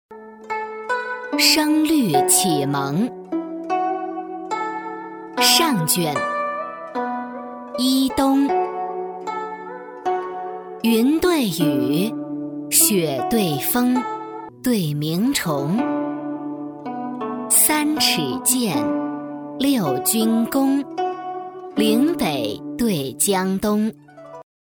女150-课件（声律启蒙）
女150-中英双语 成熟知性
女150-课件（声律启蒙）.mp3